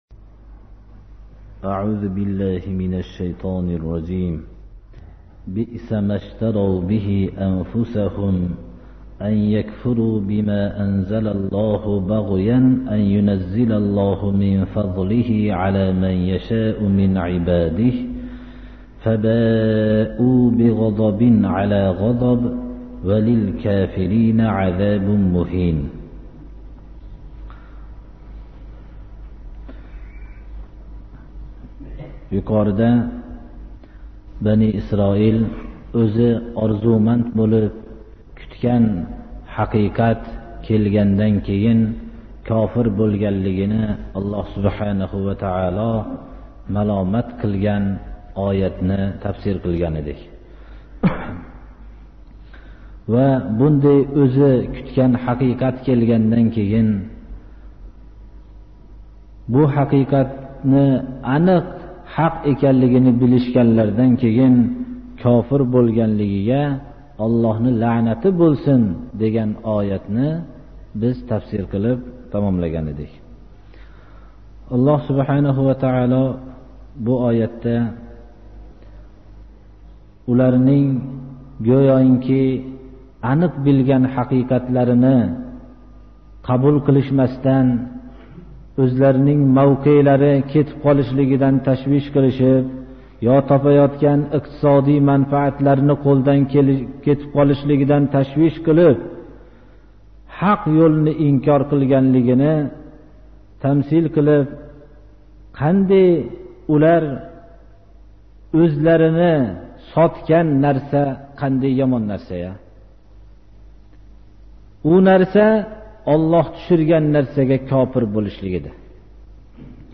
10-Бақара сурасининг тафсири